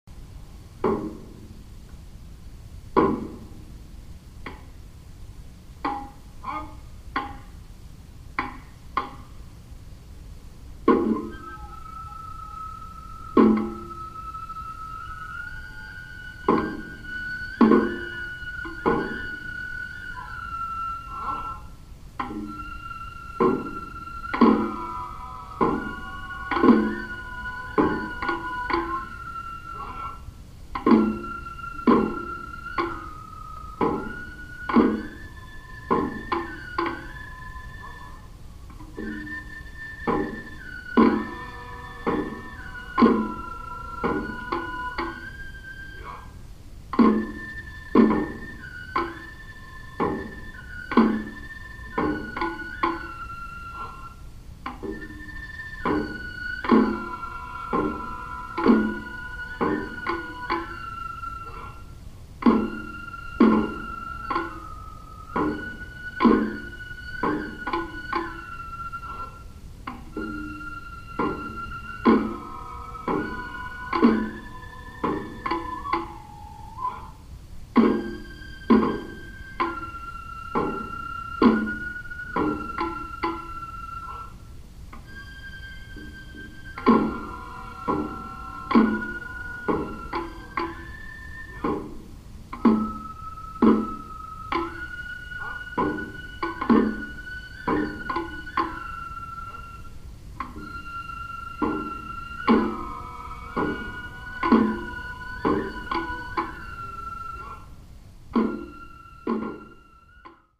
大太鼓，小太鼓，鼓，能管で演奏されます。
拍子は速いです。